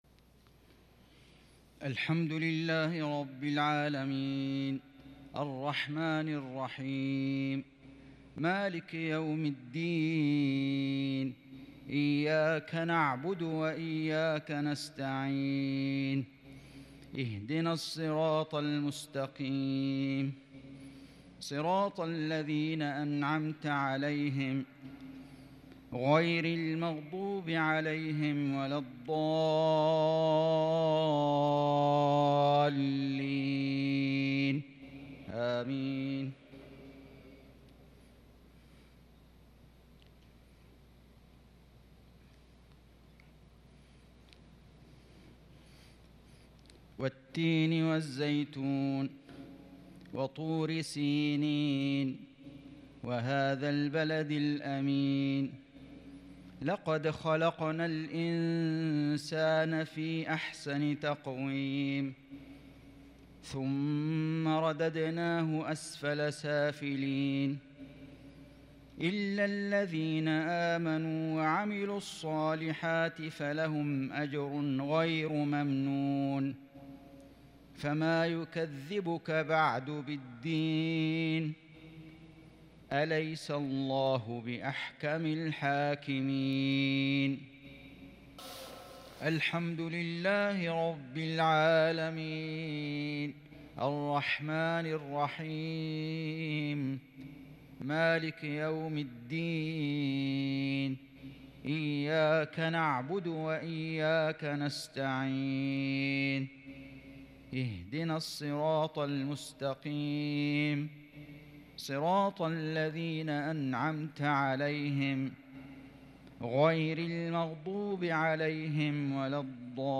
عشاء الاثنين 1-1-1443هـ سورتي التين و التكاثر | Isha prayer from Surat At-Tin and At-Takaathur 9-8-2021 > 1443 🕋 > الفروض - تلاوات الحرمين